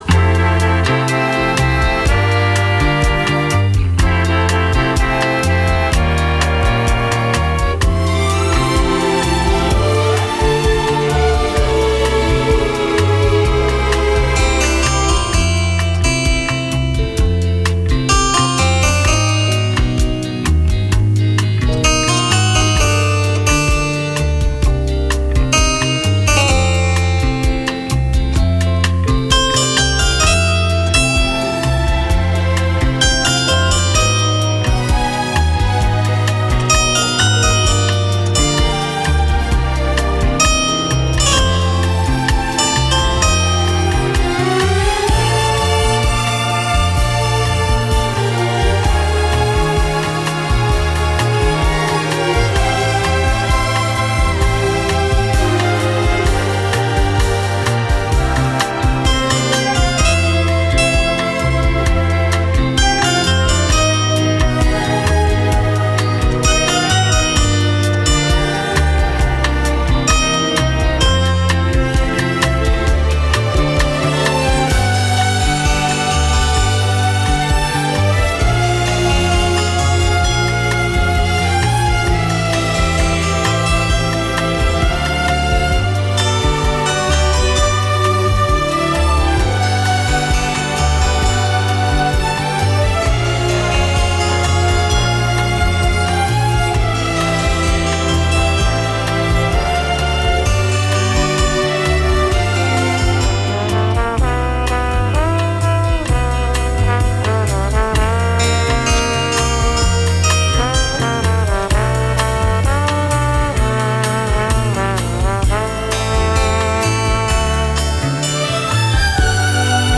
клавир
Запись 1983 в студии Радио Братиславы